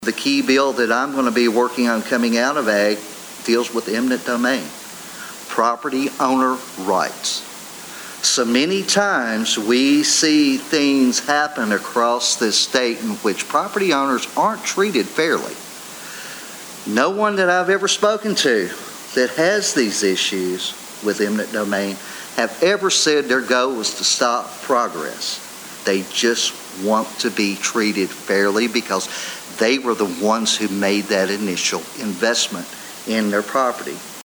8th District State Representative Walker Thomas, 9th District State Representative Myron Dossett, and 3rd District State Senator Craig Richardson shared some of those Thursday night at a Town Hall.